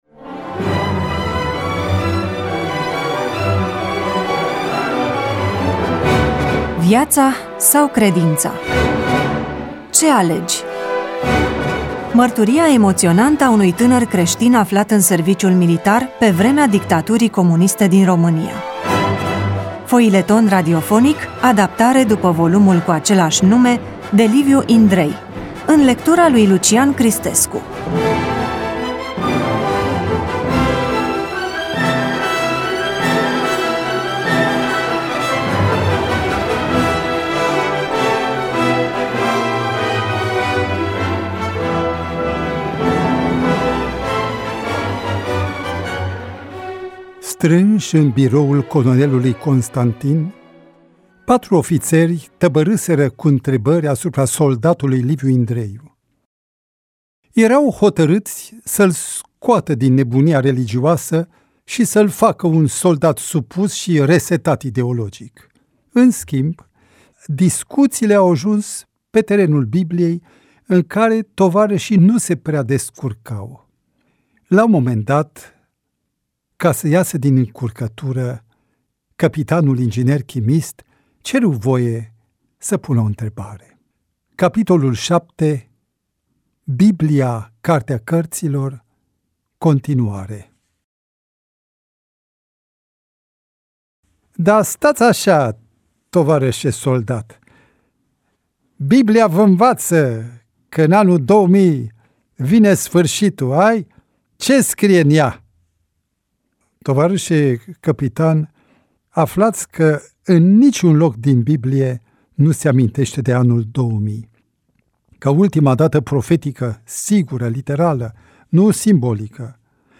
EMISIUNEA: Roman foileton DATA INREGISTRARII: 25.04.2025 VIZUALIZARI: 22